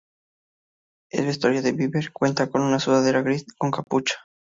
ves‧tua‧rio
/besˈtwaɾjo/